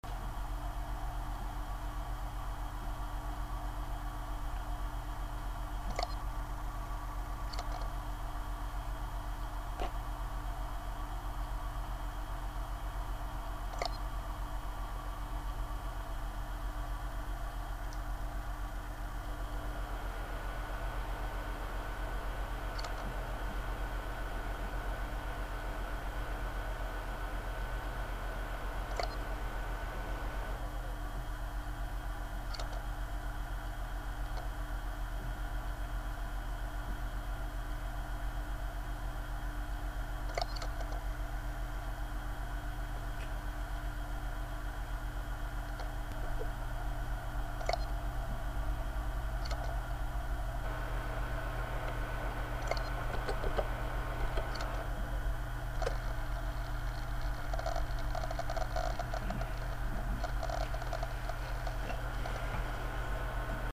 J'ai un petit souci avec mon disque dur. Je trouve qu'il fait des bruits tres bizarres.
A la fin du fichier audio, j'ai coupé pour vous faire entendre une activité que je qualifie de normale.